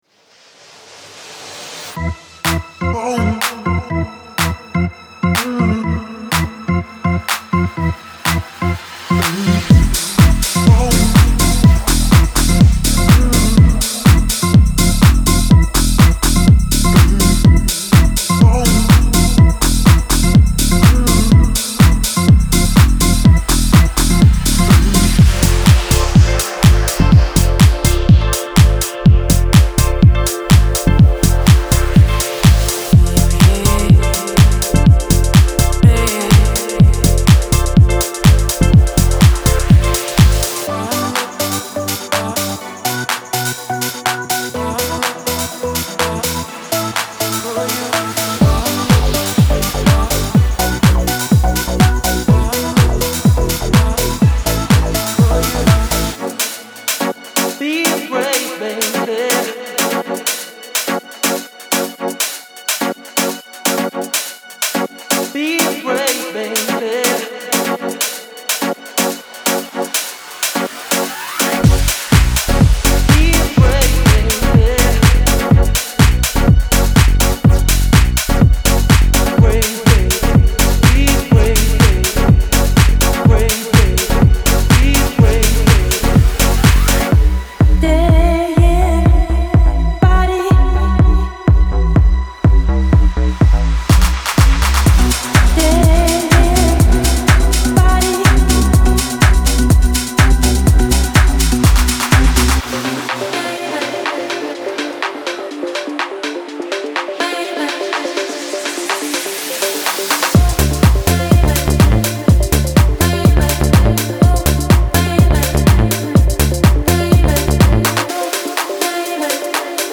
Genre:Classic House
デモサウンドはコチラ↓
124 BPM